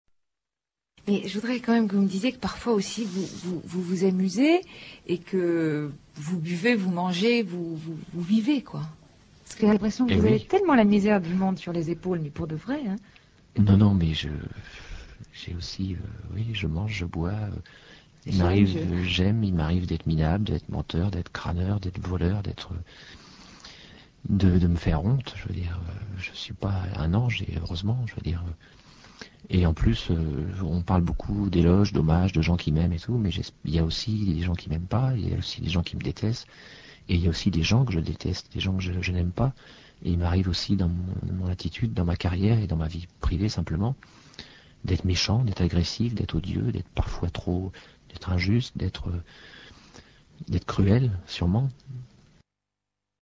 Interview de Renaud à RTL le 9 octobre 1989